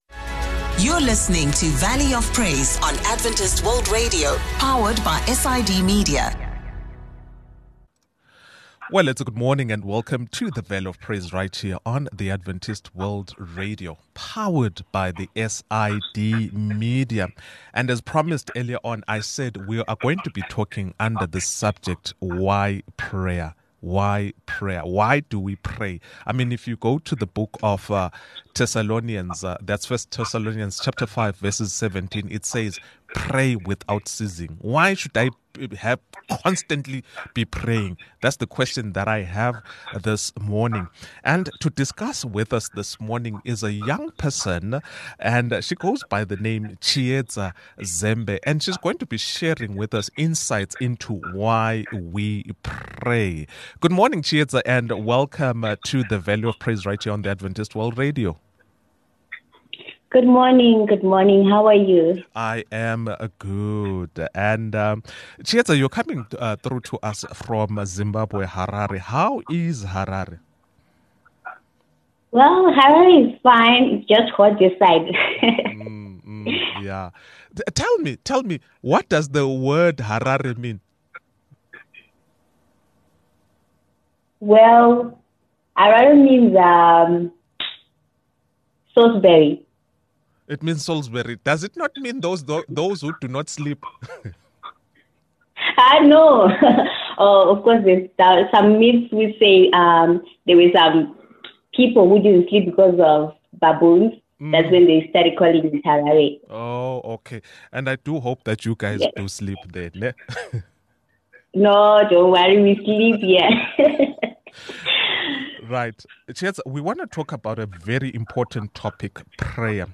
Join us for an insightful conversation